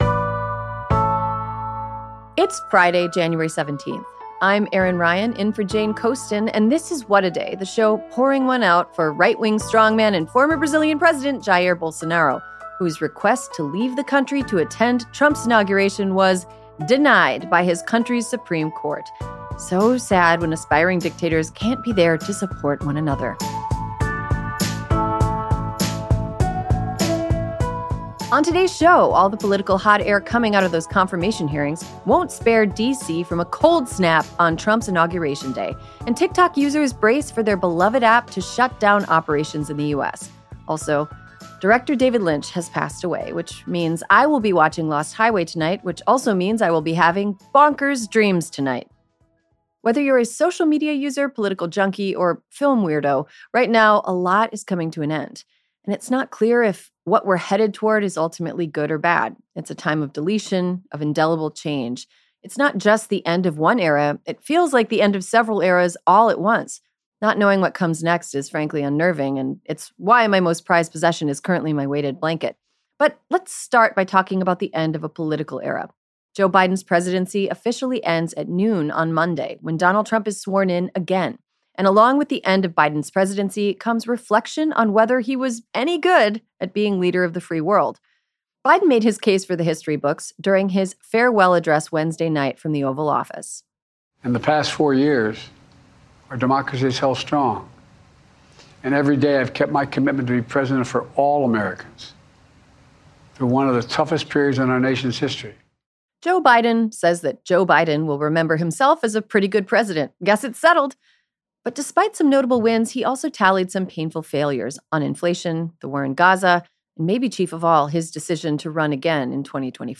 Later in the show, a Pasadena resident reflects on evacuating from the LA fires.